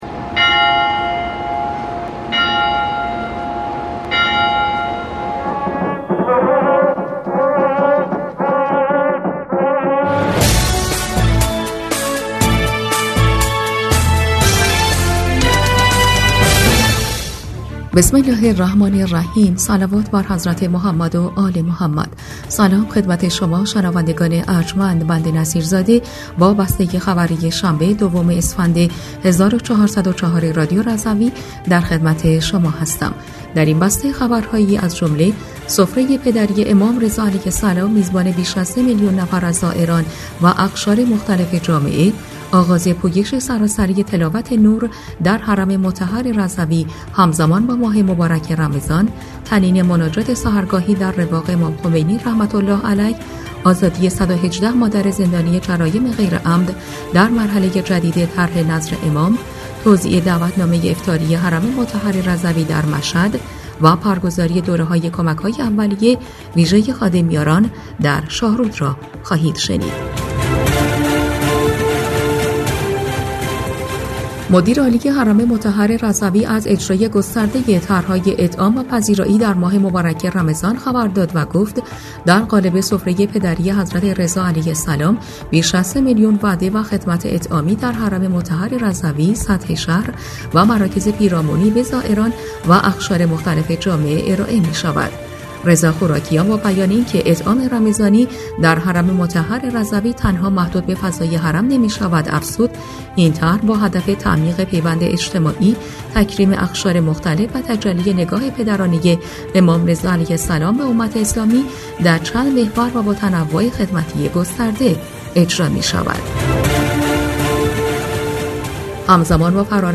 بسته خبری ۲ اسفند ۱۴۰۴ رادیو رضوی؛